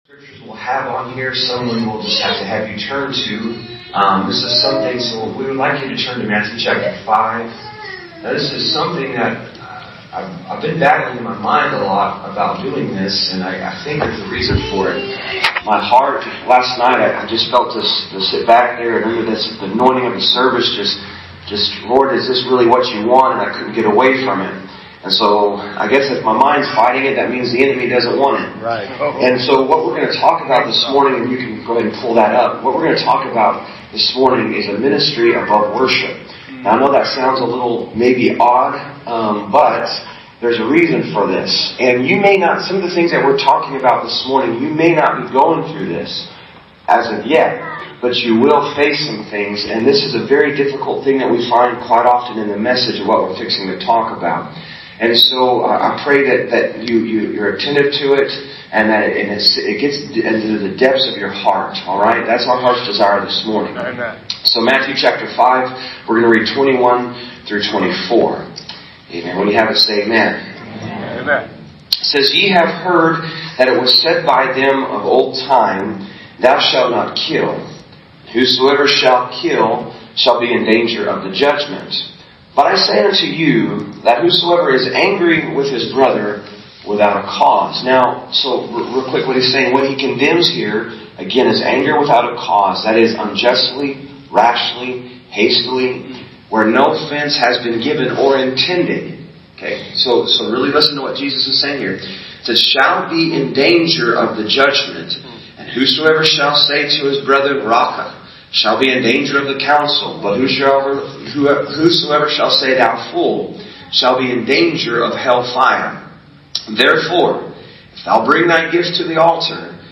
2022 Sermons - Believers Youth Camp
From Series: "2025 Sermons"